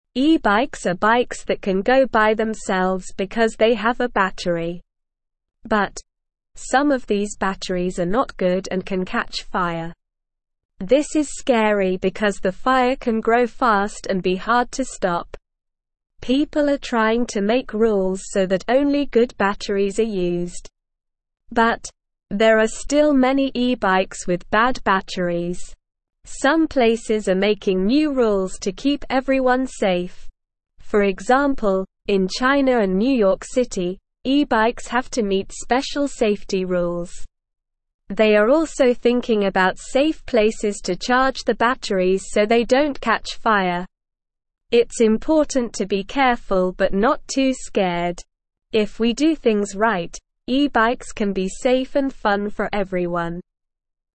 Slow
English-Newsroom-Beginner-SLOW-Reading-E-bikes-Safe-and-Fun-with-Good-Batteries.mp3